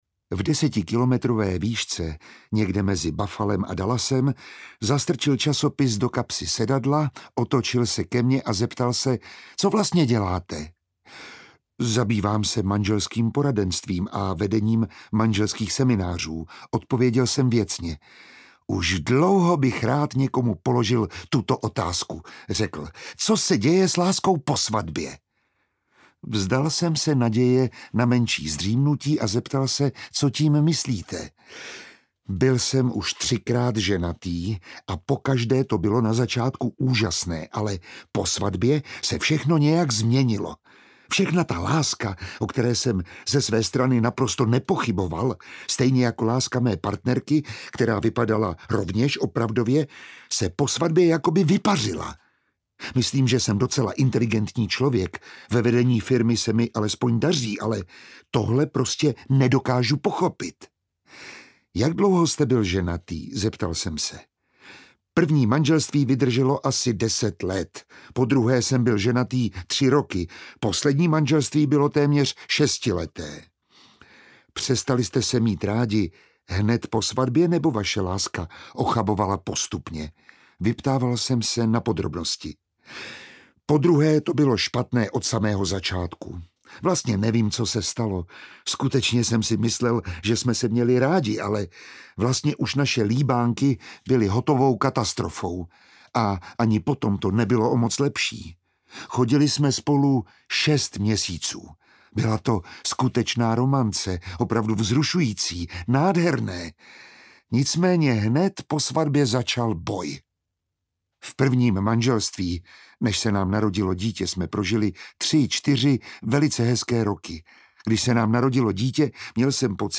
Audiobook
Read: Miroslav Táborský